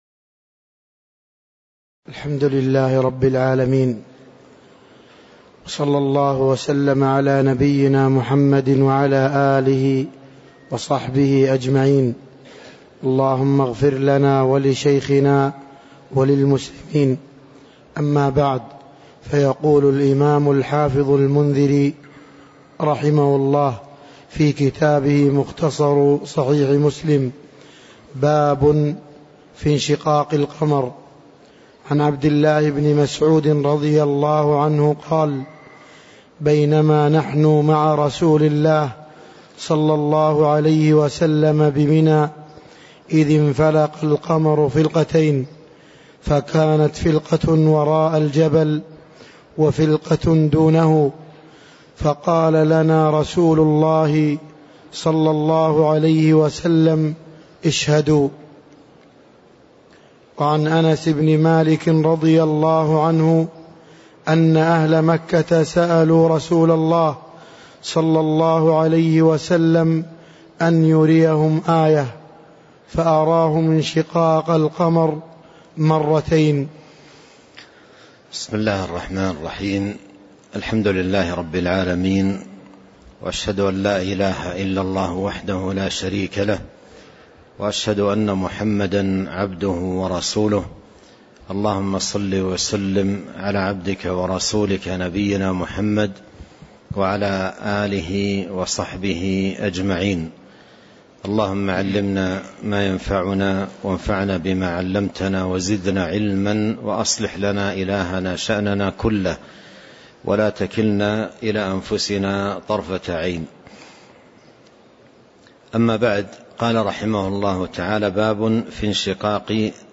تاريخ النشر ٢١ شعبان ١٤٤٣ هـ المكان: المسجد النبوي الشيخ